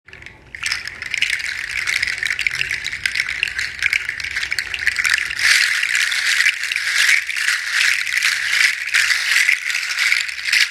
• large seed shaker originally from Togo
• loud hollow clacking sound
Rattan-ring.m4a